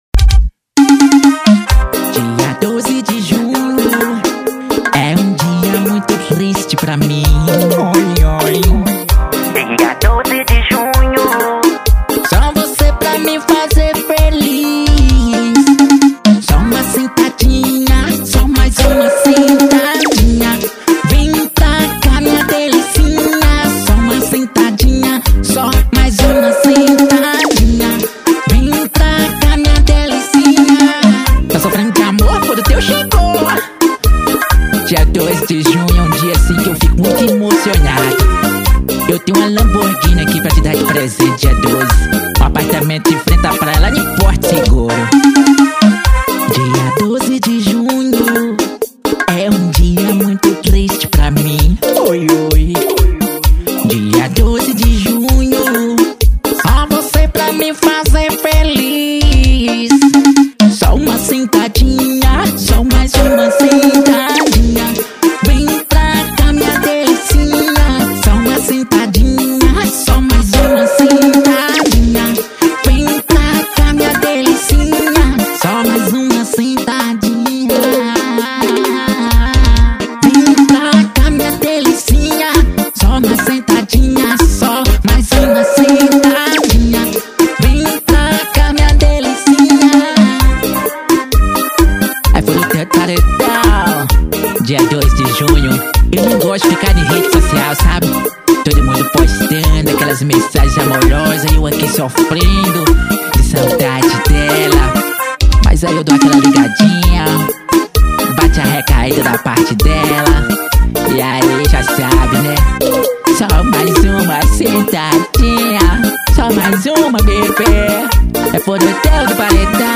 EstiloBregadeira